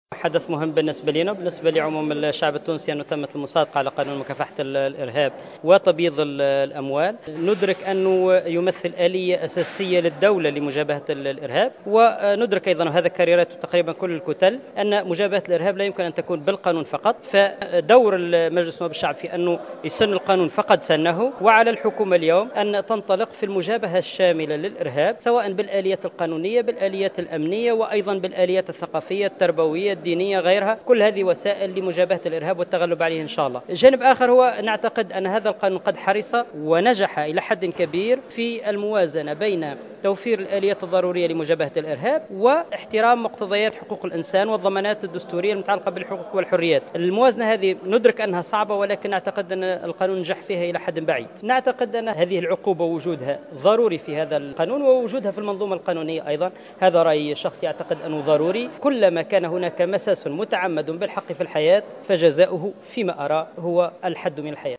أكد النائب عن حركة النهضة الحبيب خضر في تصريح